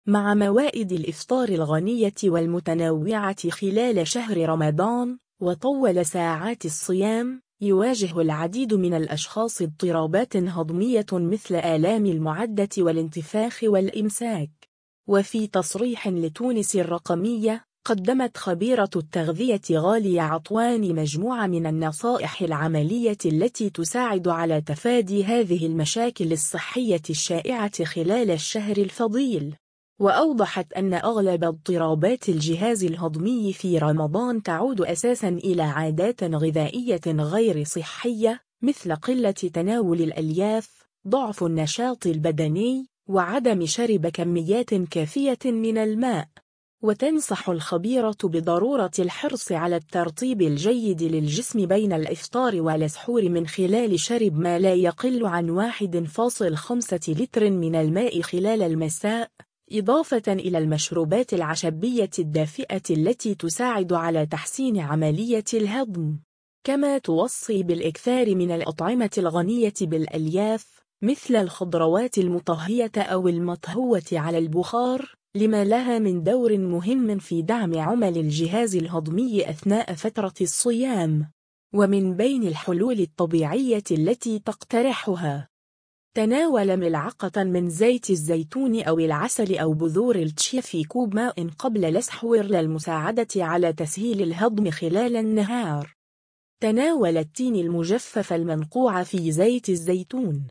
اضطرابات الهضم في رمضان : أخصائية تغذية تكشف الأسباب والحلول [فيديو]